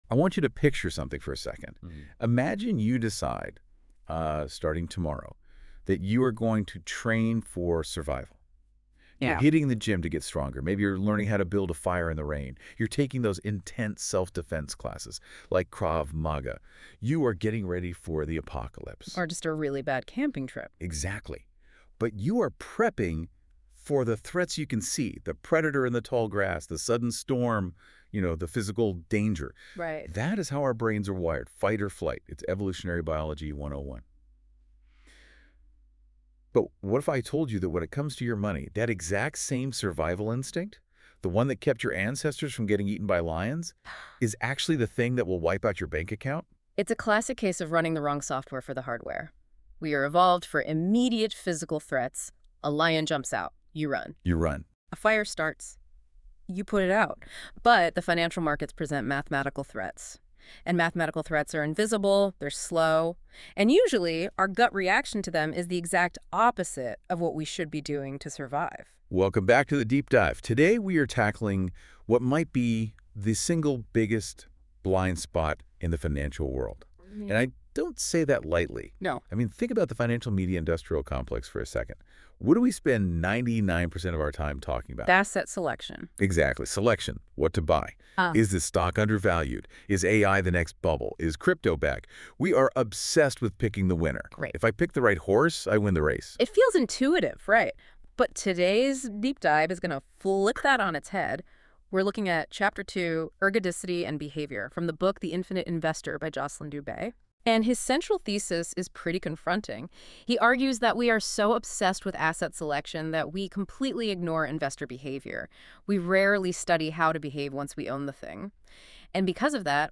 🎧 Listen to the deep-dive discussion – Surviving the Math of Ruin(19:20 min)